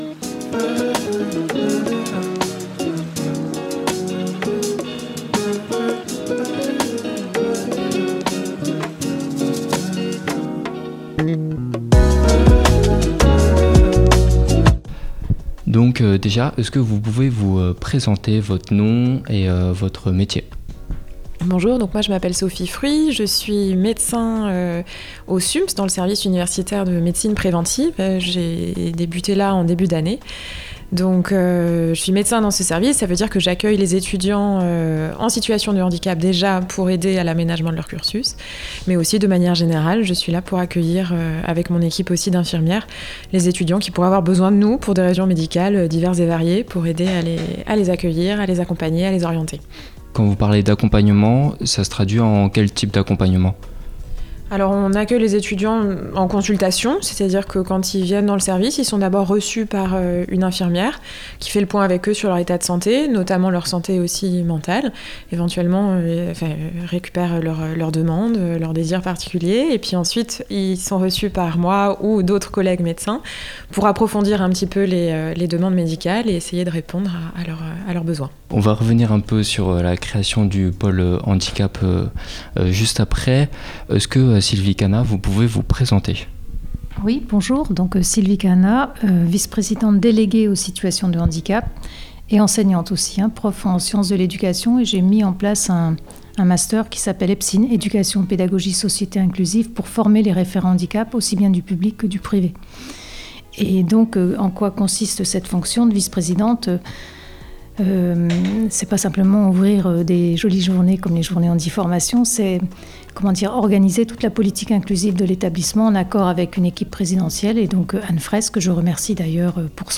02_Reportage_EVP.mp3